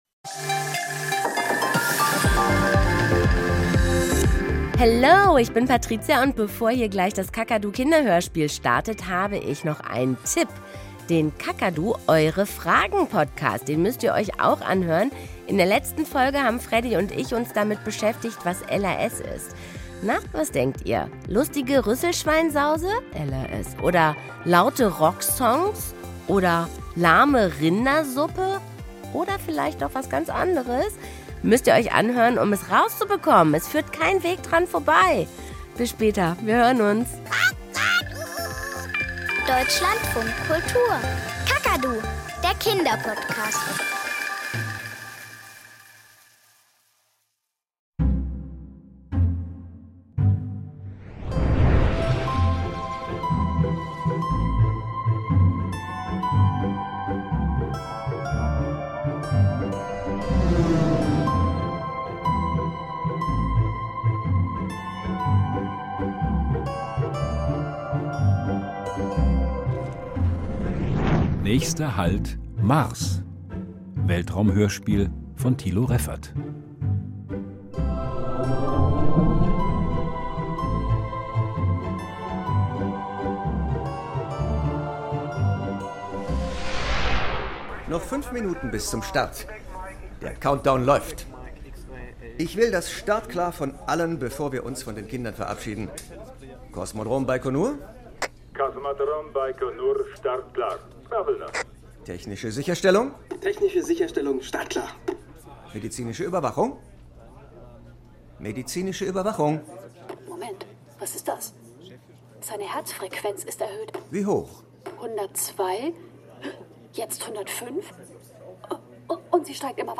Kinderhörspiel - Nächster Halt: Mars